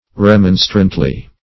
remonstrantly - definition of remonstrantly - synonyms, pronunciation, spelling from Free Dictionary Search Result for " remonstrantly" : The Collaborative International Dictionary of English v.0.48: Remonstrantly \Re*mon"strant*ly\, adv.